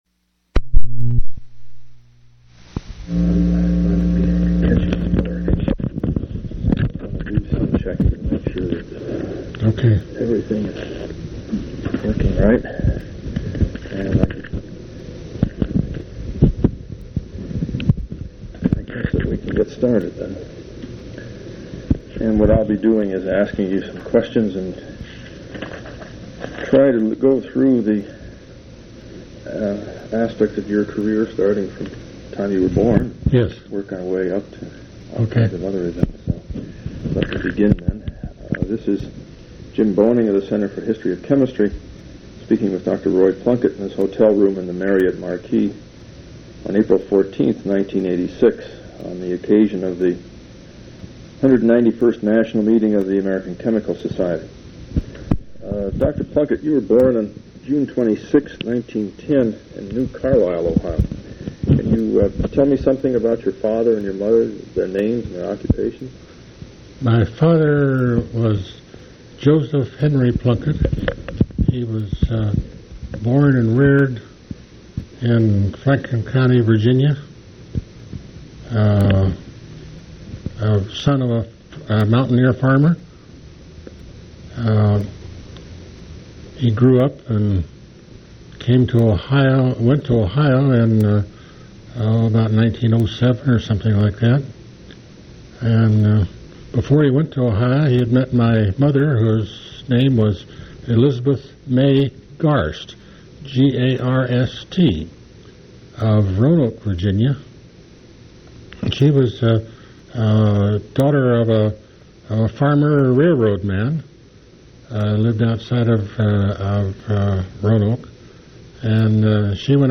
Complete transcript of interview